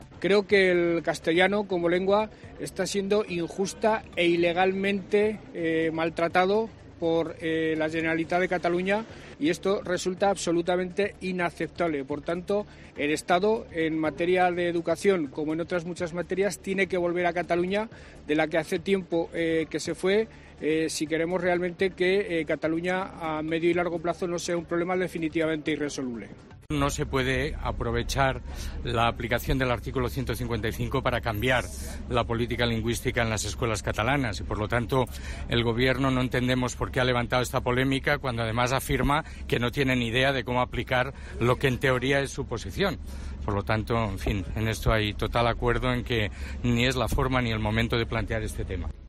En declaraciones a los periodistas en la localidad madrileña de Aranjuez, donde participa en el Comité Federal del PSOE, Lambán ha incidido en que todos los responsables políticos del país deben intentar "por todos los medios" que Cataluña salga del "esperpento" y regrese cuanto antes a la normalidad.